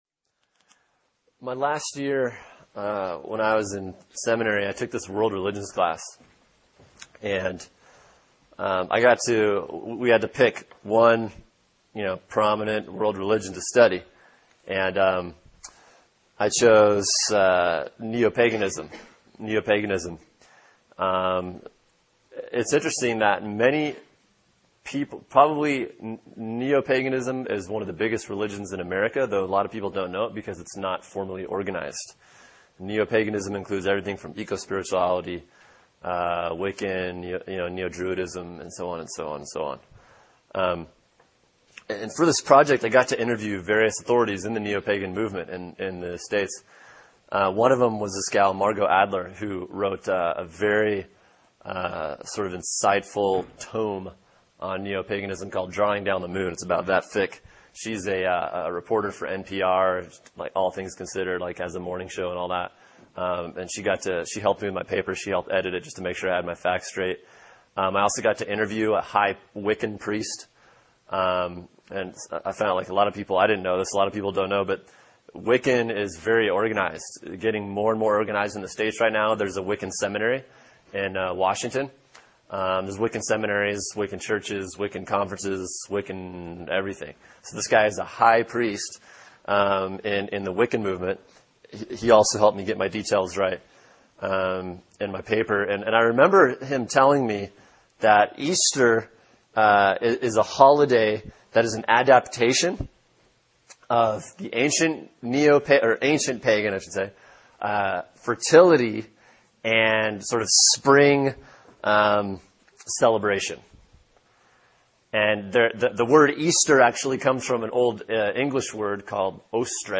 [sermon] John 20 “Alive” | Cornerstone Church - Jackson Hole
Resurrection Sunday